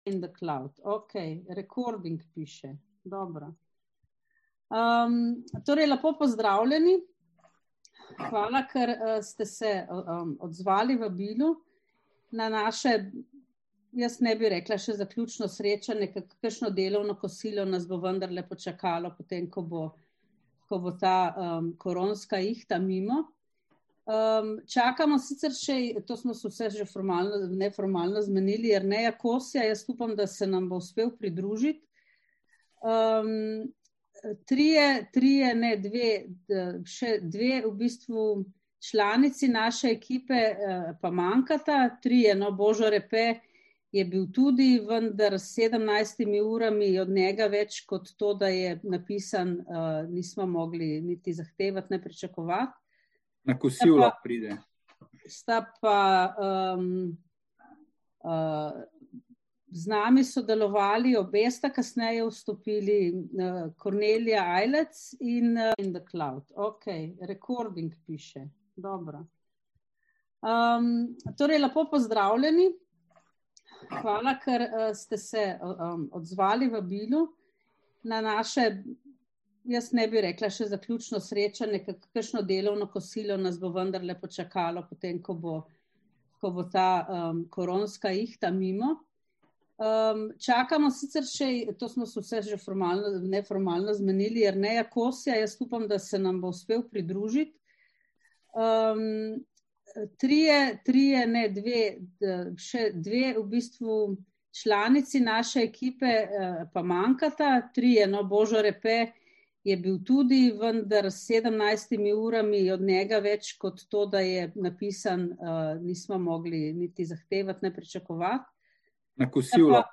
Delavnica 30. apr. 2021 (avdio posnetek sestanka)